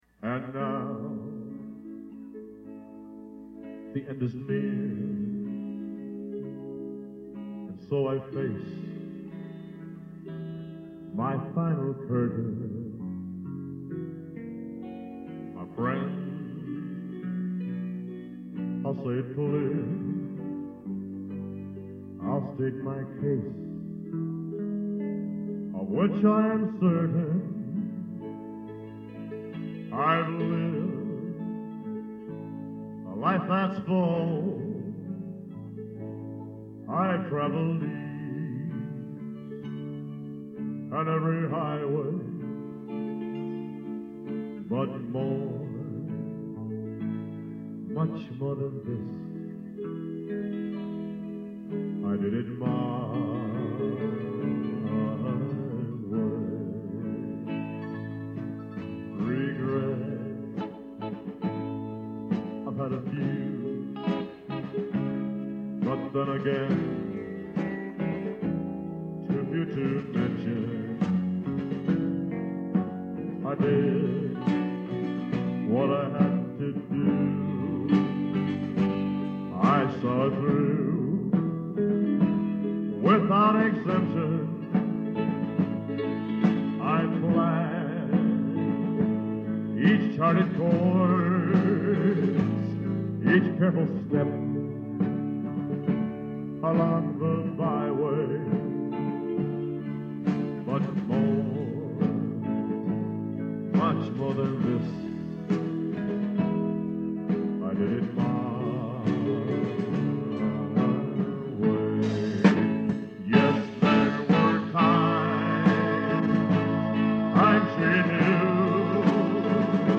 There was no board, and no one manning the tape recorder.
There is also some fade from the years that have gone by.